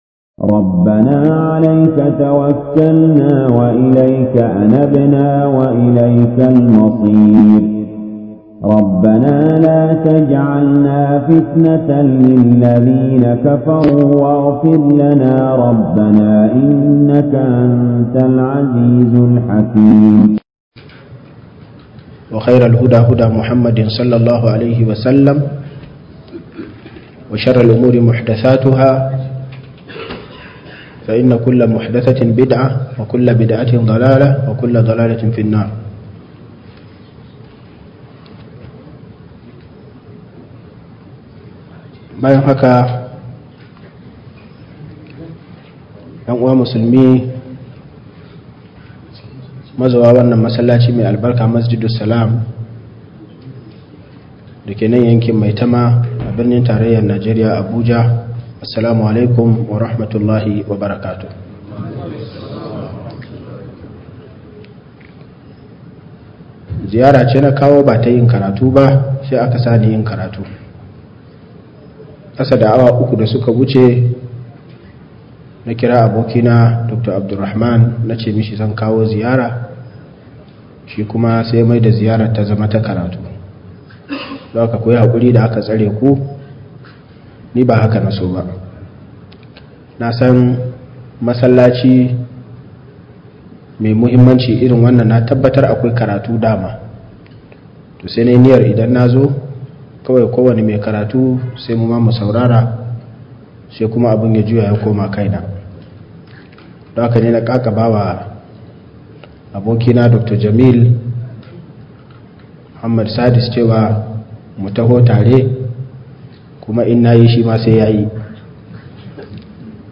Hakikanin Rayuwar Duniya - MUHADARA